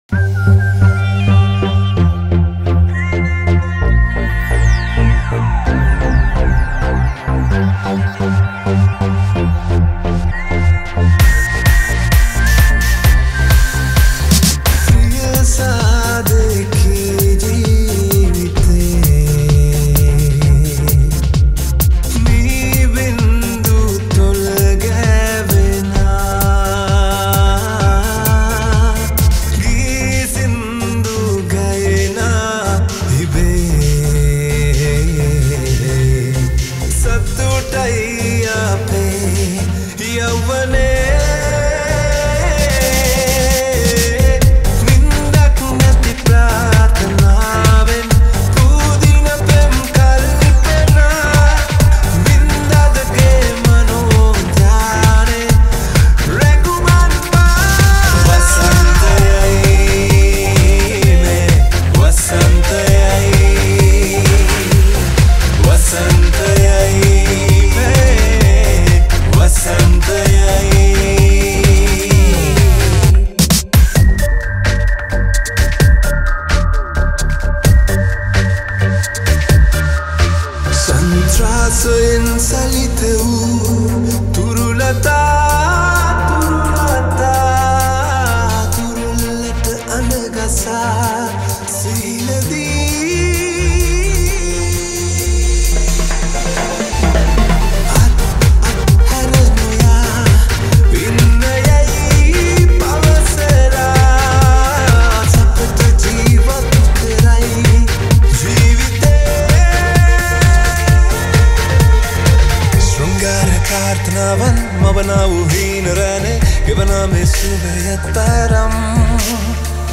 High quality Sri Lankan remix MP3 (6).